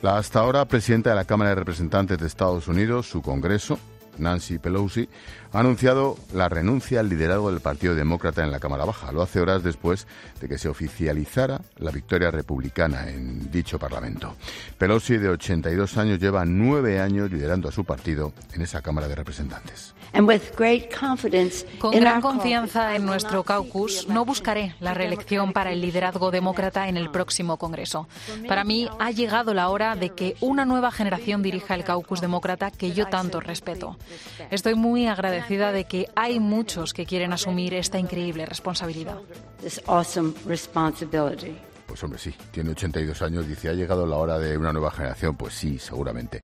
El director de 'La Linterna', Ángel Expósito, te da más detalles